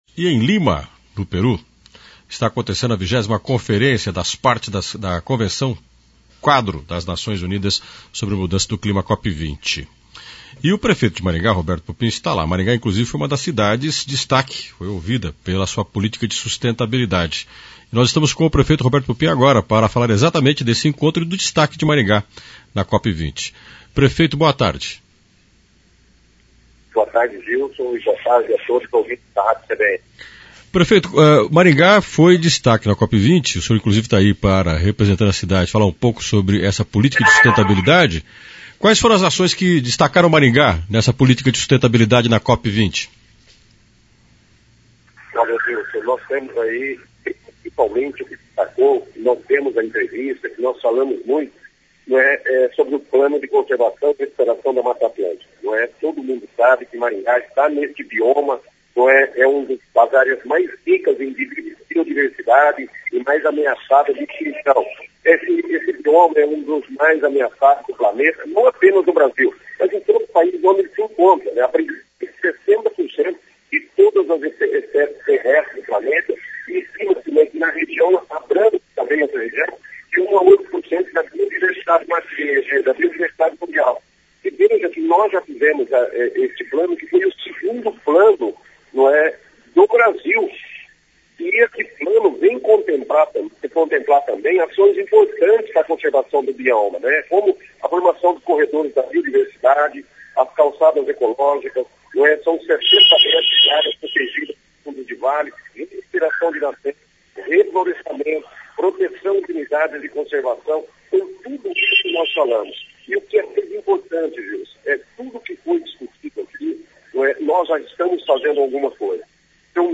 Pupin participa da 20ª Conferência das Partes da Convenção Quadro das Nações Unidas sobre Mudança do Clima. Em entrevista à CBN, ele explica as ações que levaram  Maringá a ser uma das cidades de ações voltadas à sustentabilidade.
Entrevista Prefeito Pupin G.A 05-12 AO VIVO.mp3